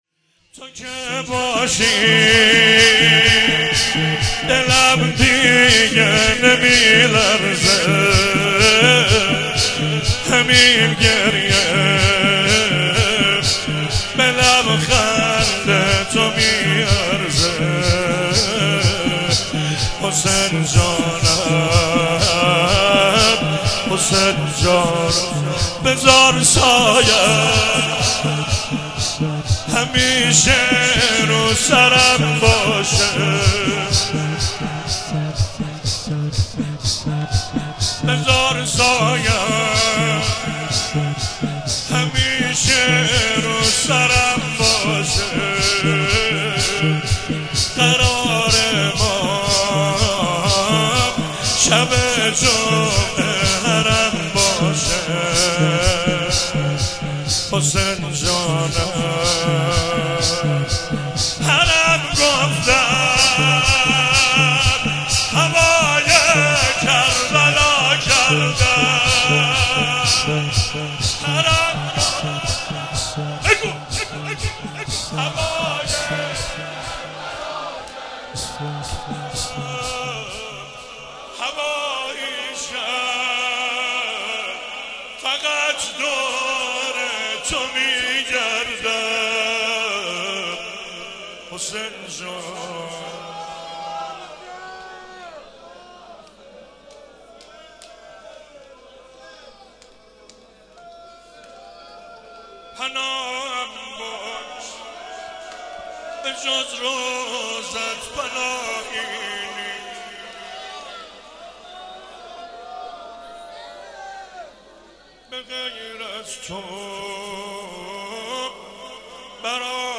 چهاراه شهید شیرودی حسینیه حضرت زینب (سلام الله علیها)
شور